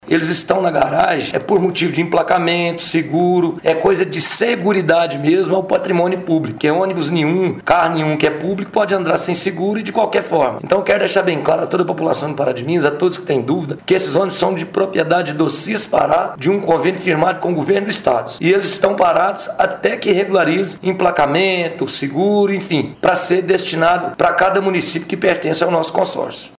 A confirmação do procedimento foi dada ao Jornal da Manhã pelo presidente do Cispará, Vandeir Paulino da Silva: